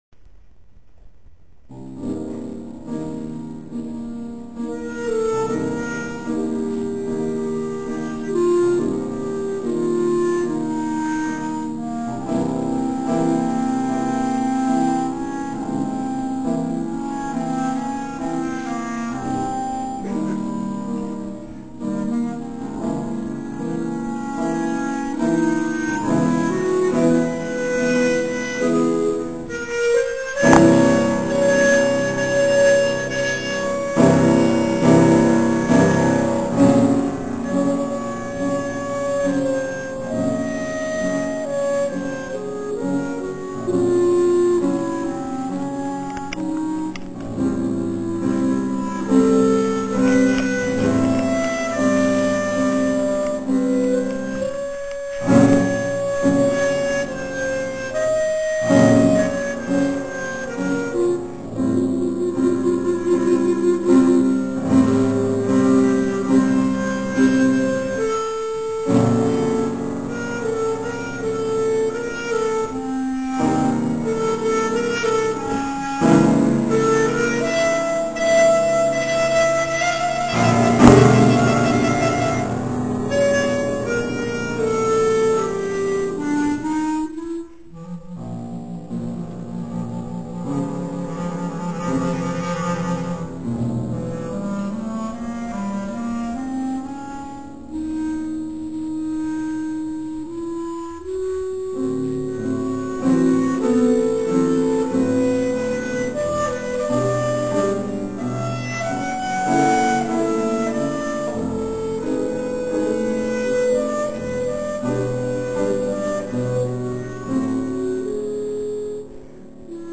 27. komorní koncert na radnici v Modřicích
Ukázkové amatérské nahrávky WMA:
klarinet
klavír